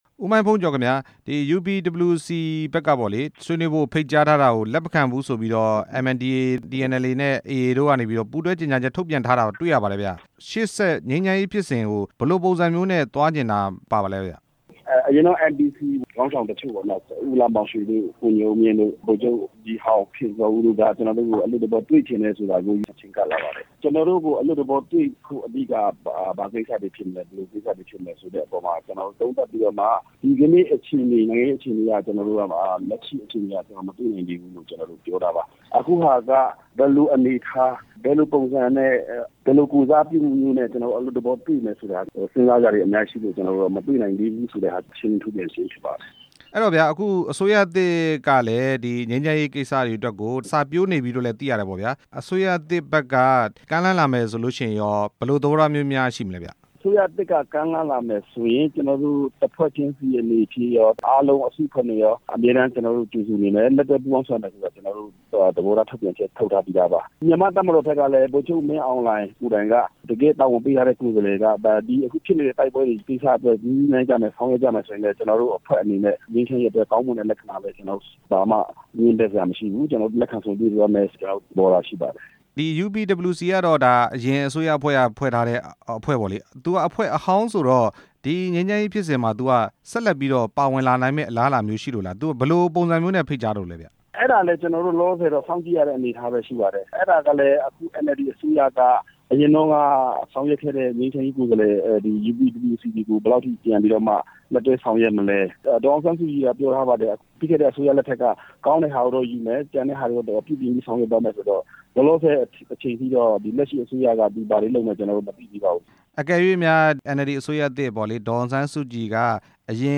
အလွတ်သဘော ဆွေးနွေးဖို့ ကမ်းလှမ်းချက် လက်မခံတဲ့အပေါ် မေးမြန်းချက်